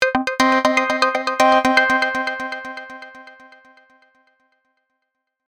こちらは4つのオシレーターのピッチがそれぞれ、入力されたノートの「+1オクターブ」「+3半音（短3度）」「-1オクターブ」「+7半音（完全5度）」に設定されています。上で演奏しているノートは C4 なので、実際に鳴っている音は C5, D#4, C3, G4 ですね。